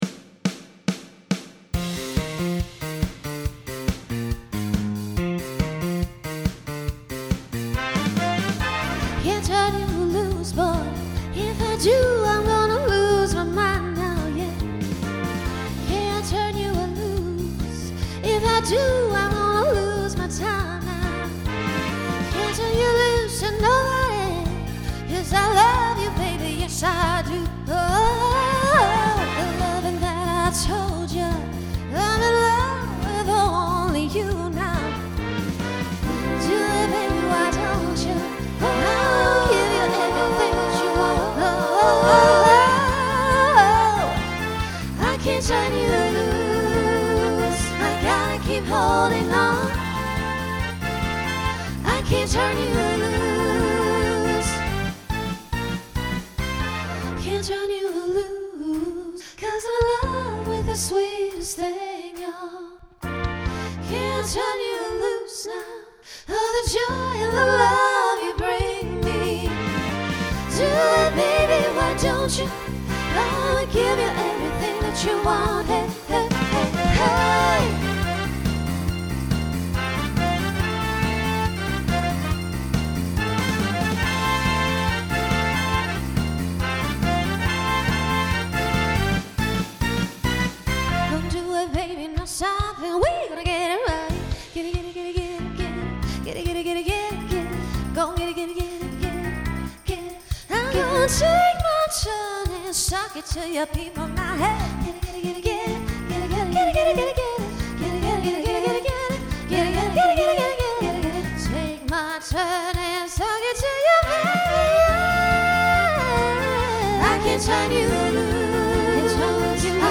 Genre Pop/Dance , Rock Instrumental combo
Transition Voicing SSA